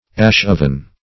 Search Result for " ash-oven" : The Collaborative International Dictionary of English v.0.48: Ash-furnace \Ash"-fur`nace\, Ash-oven \Ash"-ov`en\, n. A furnace or oven for fritting materials for glass making.